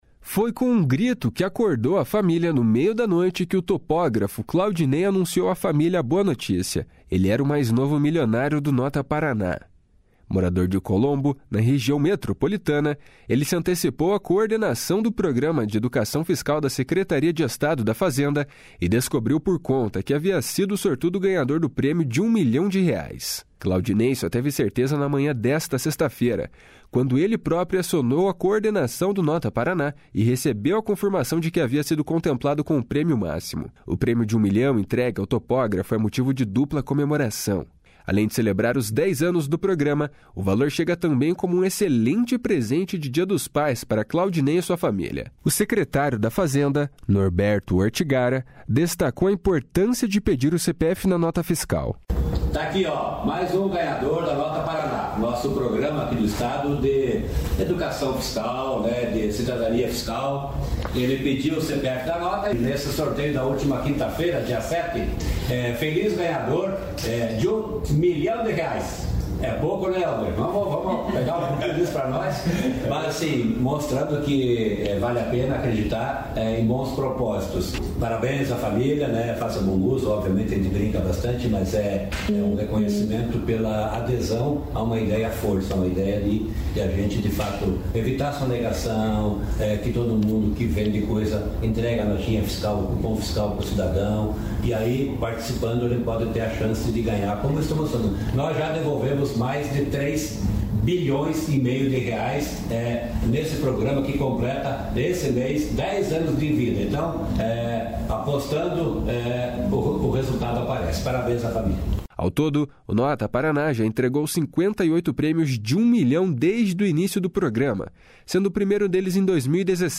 O secretário da Fazenda, Norberto Ortigara, destacou a importância de pedir o CPF na nota fiscal. // SONORA NORBERTO ORTIGARA //